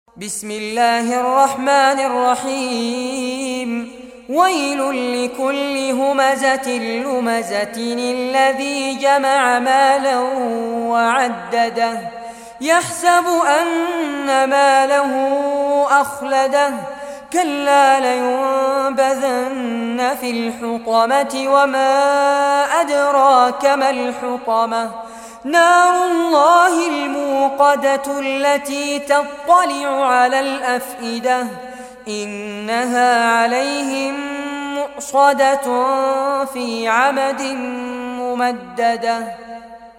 Surah Al-Humazah Recitation by Fares Abbad
Surah Al-Humazah, listen or play online mp3 tilawat / recitation in arabic in the beautiful voice of Sheikh Fares Abbad.